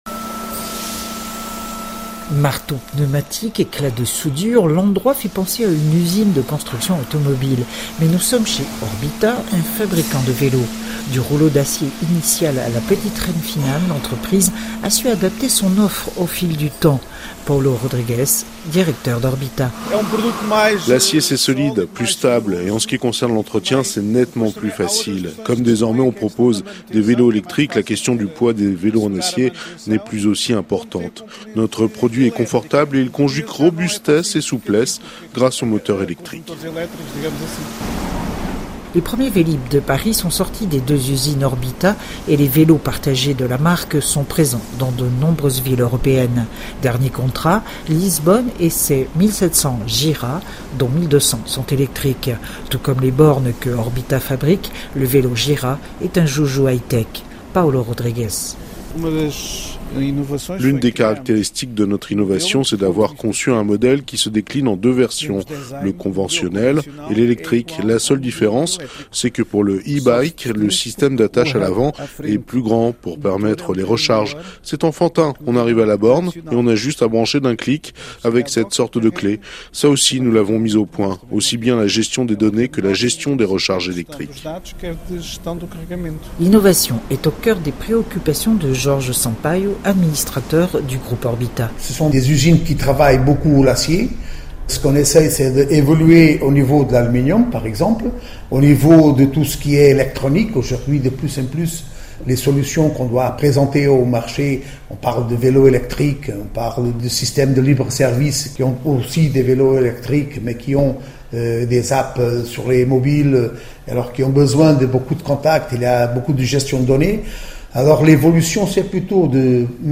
RFI – Chronique Transports du 14 avril 2018, « Agueda, la “bike valley” du Portugal » de 4’38 » à 5’52 »